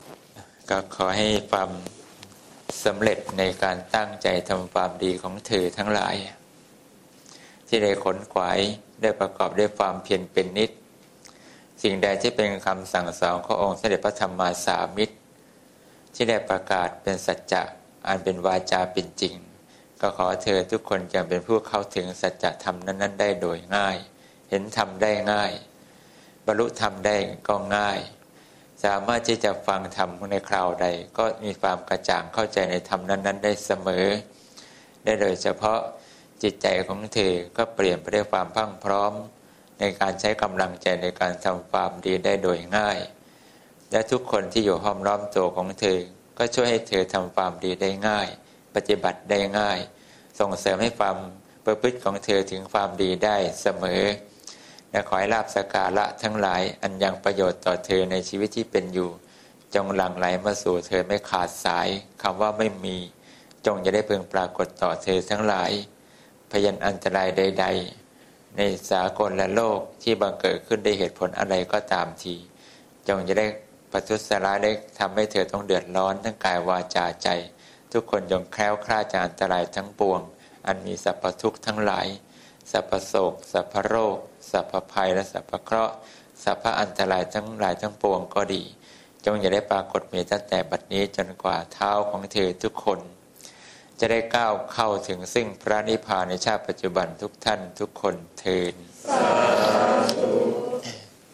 หลวงพ่อให้พร